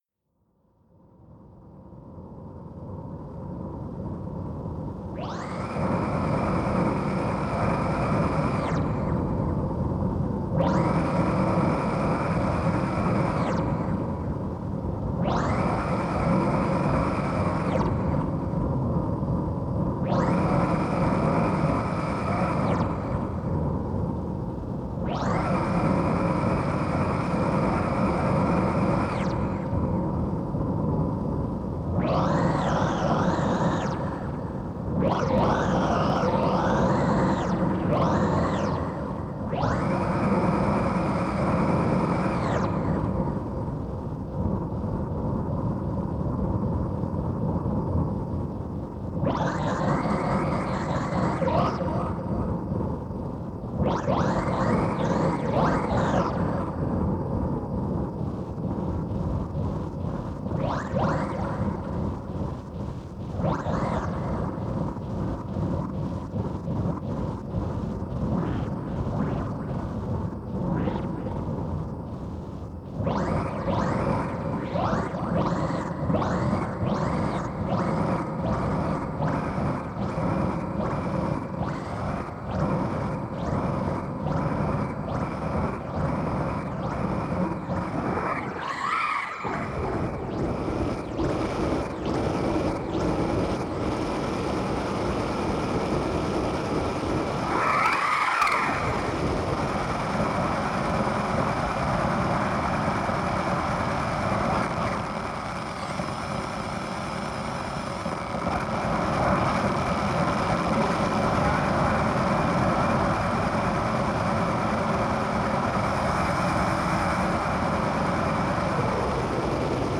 STAR SHEPHERD SYNTHESIZER
It is very noisey, crackley and sometimes starts doing its own thing like some sentient synthesizer being that is alive.
Here are some sound samples recorded directly from the synth. With no overdubbing nor external processing:
Star Sepherd Synthesizer Alien Sounds.mp3